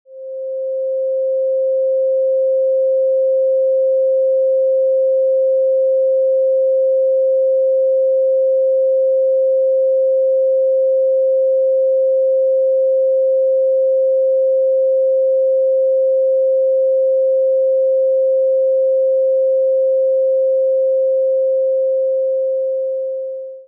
528Hz_mixdown.mp3